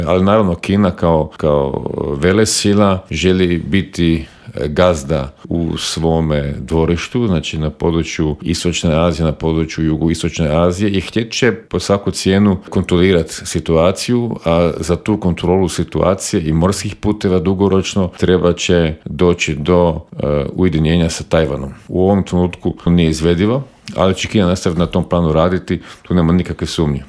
Bivšeg ministra vanjskih i europskih poslova Miru Kovača u Intervjuu Media servisa upitali smo - je li ga iznenadio postupak SAD-a?